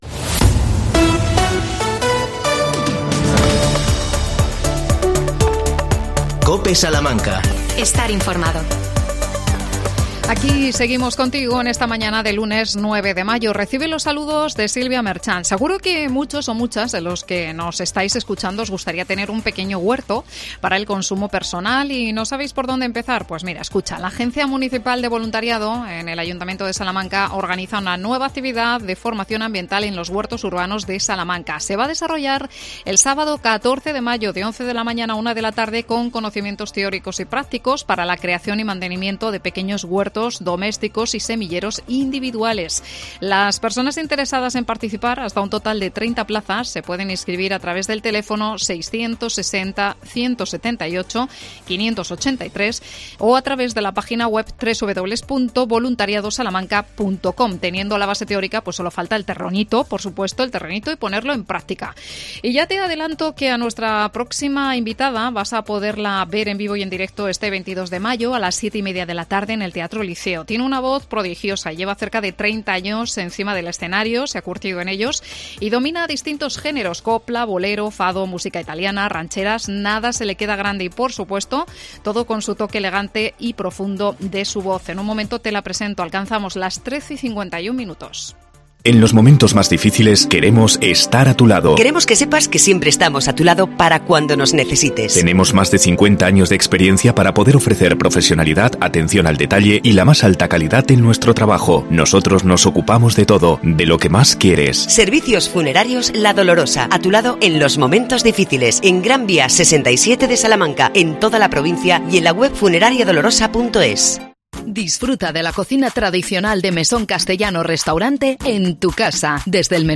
AUDIO: Entrevista a Pasión Vega. Presenta en Salamanca su último trabajo "Todo lo que Tengo"